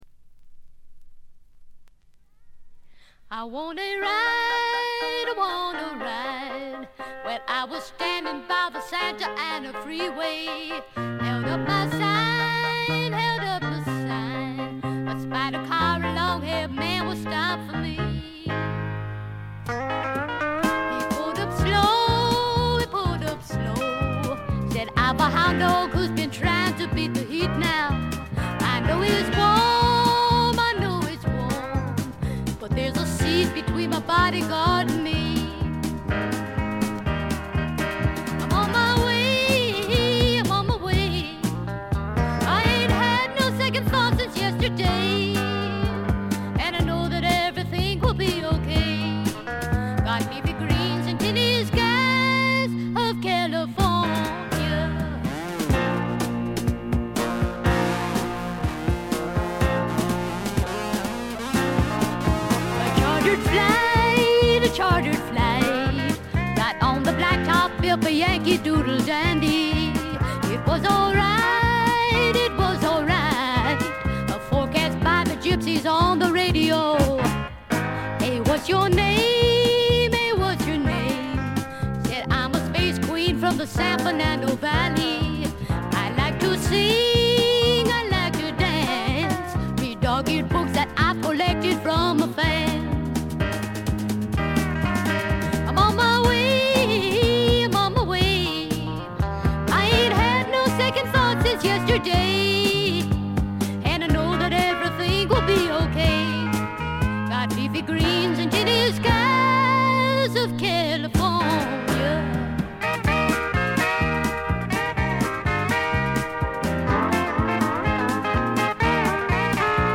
全曲自作で良曲が並び、リリカルなピアノとコケティッシュなヴォーカルがとても良いです。
試聴曲は現品からの取り込み音源です。